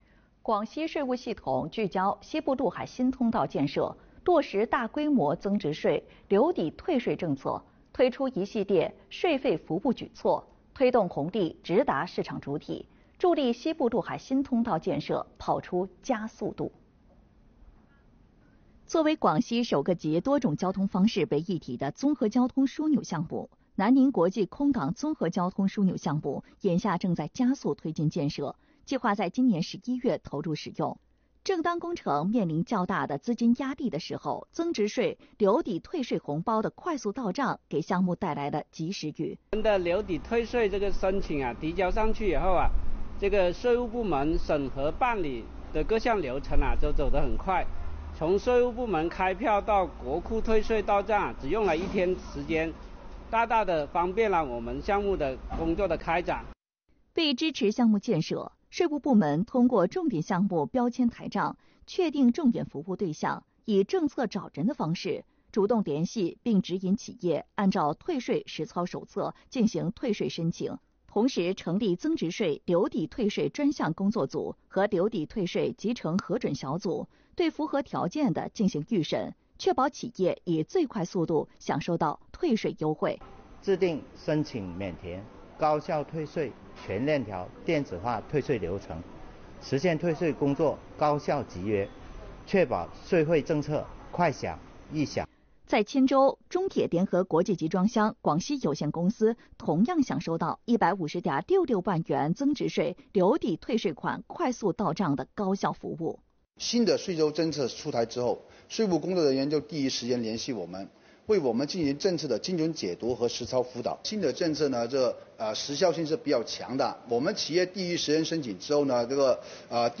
电视报道|留抵退税“高效办” 通道建设“加速跑”